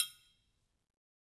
Triangle3-HitFM_v2_rr2_Sum.wav